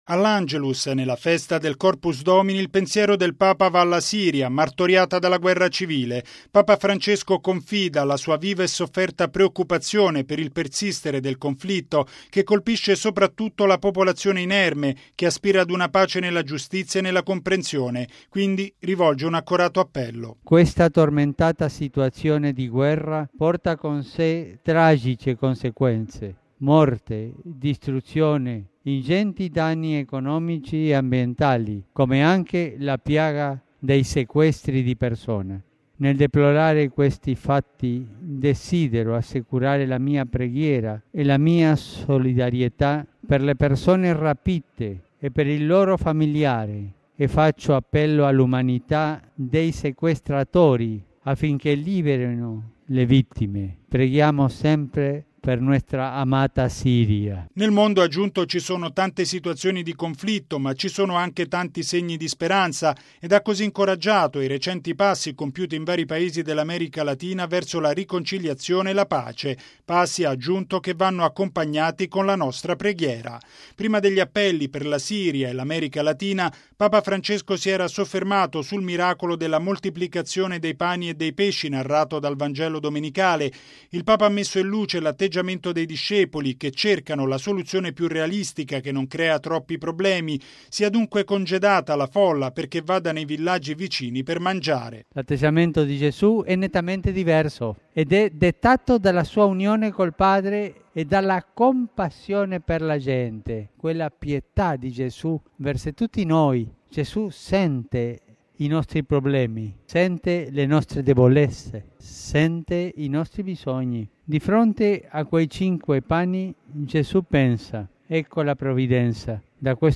E’ quanto sottolineato da Papa Francesco all’Angelus, in Piazza San Pietro, gremita di fedeli. Il Papa ha, quindi, rivolto un accorato appello per la liberazione delle persone sequestrate in Siria, assicurando la sua preghiera per la pace nel martoriato Paese.